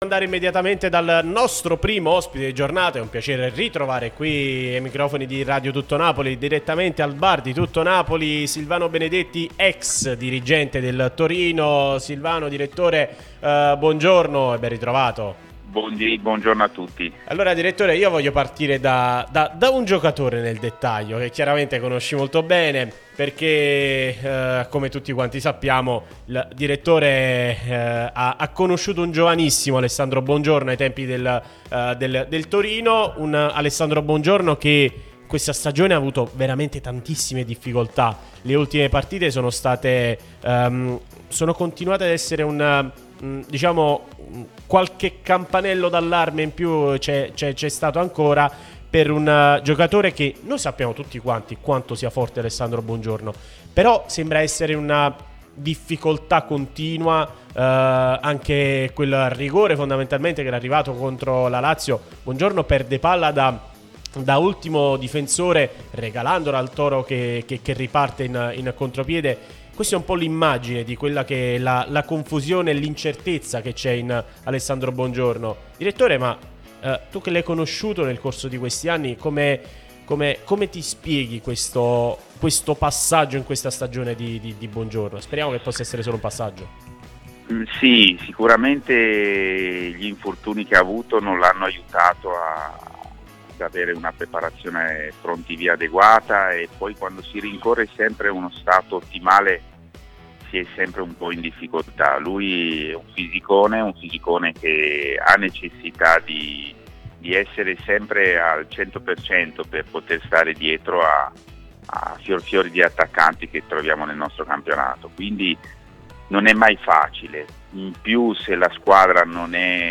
Podcast Ex dirigente Torino: "Buongiorno?